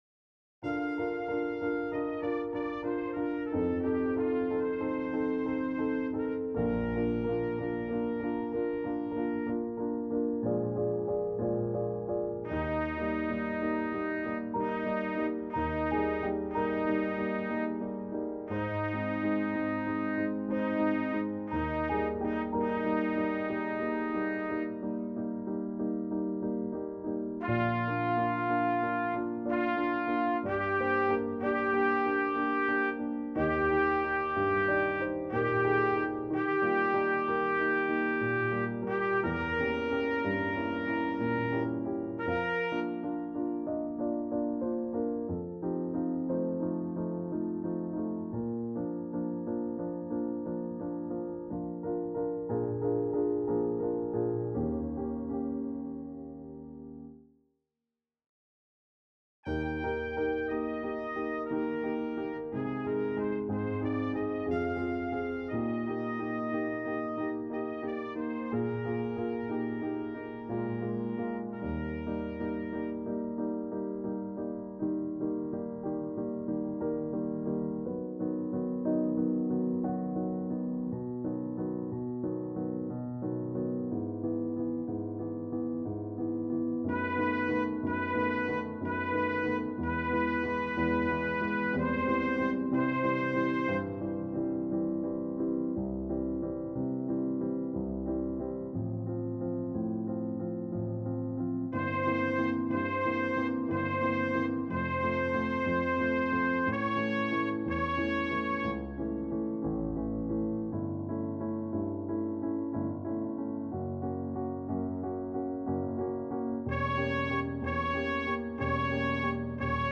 장로성가단 연습음원